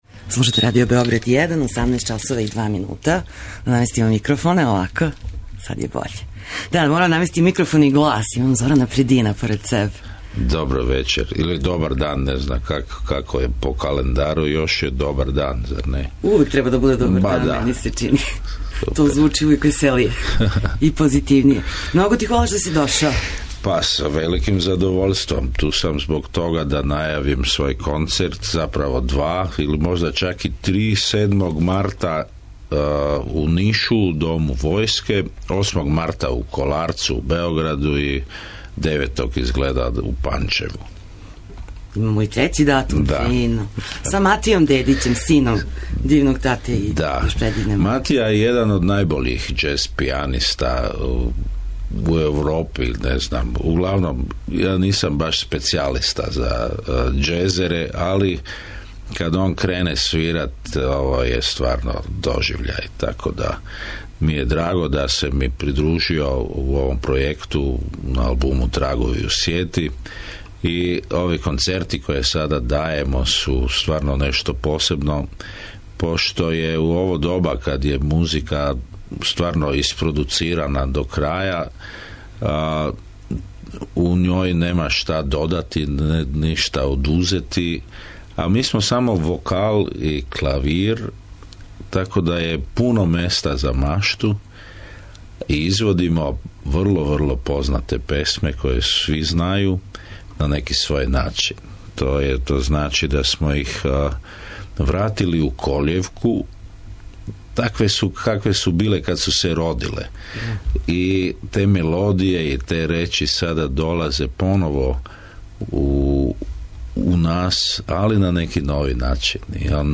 Гост емисије је Зоран Предин, један од најзначајнијих уметника у региону (Лацни Франц, соло пројекти, сарадња са музичарима... ).